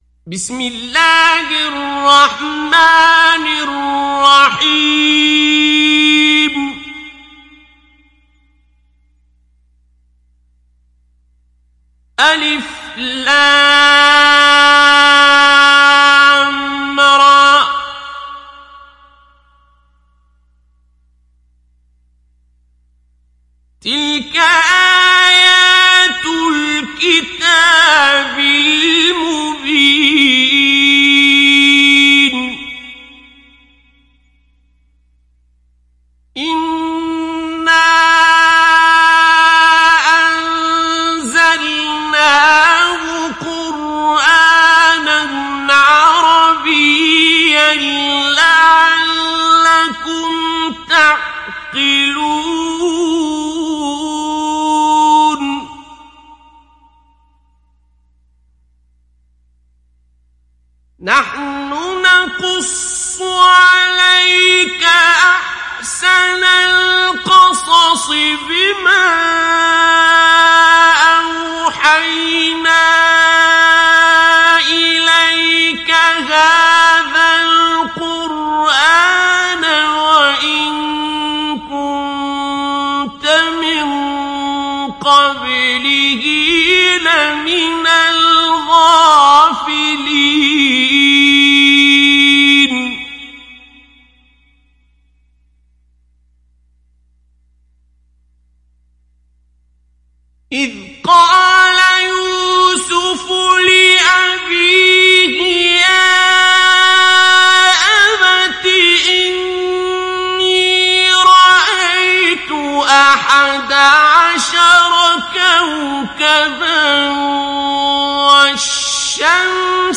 Yusuf Suresi İndir mp3 Abdul Basit Abd Alsamad Mujawwad Riwayat Hafs an Asim, Kurani indirin ve mp3 tam doğrudan bağlantılar dinle
İndir Yusuf Suresi Abdul Basit Abd Alsamad Mujawwad